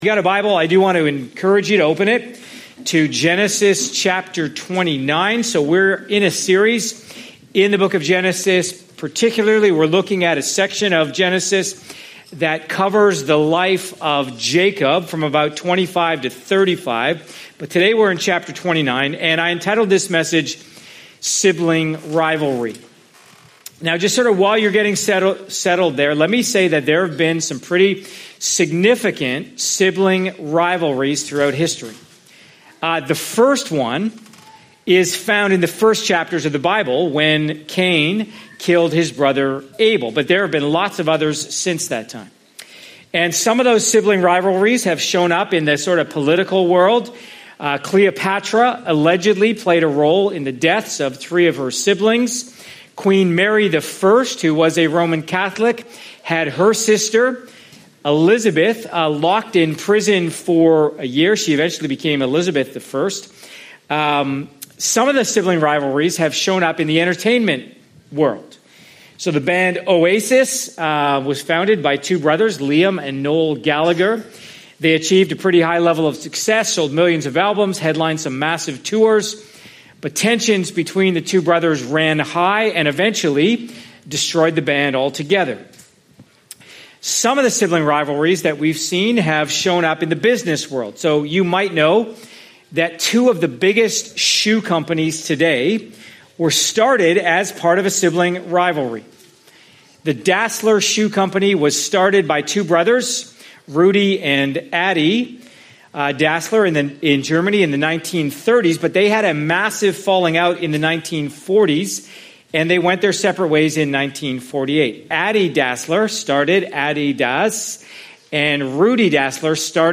Part of our series, “ Straight Lines with Crooked Sticks ,” following the life of Jacob in the book of Genesis. CLICK HERE for other sermons from this series.